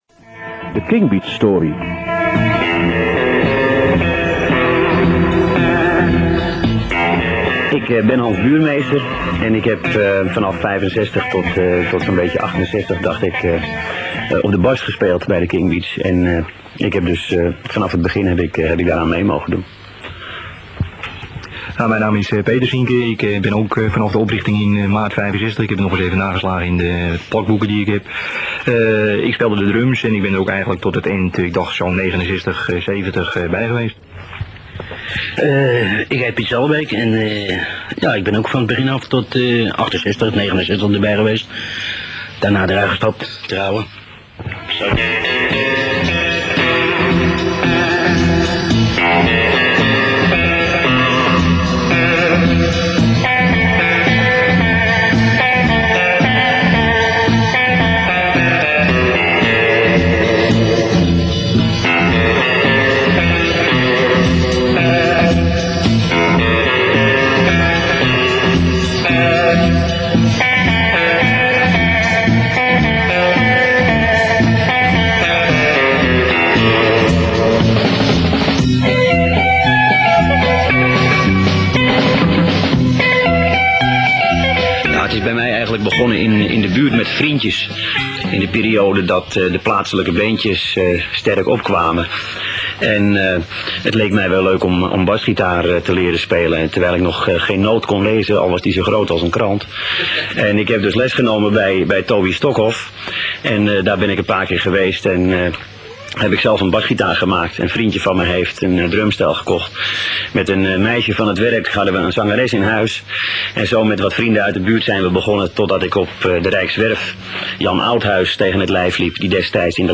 Kingbeats_Reunie_deel_1.wma